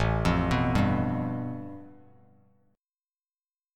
A7sus2#5 chord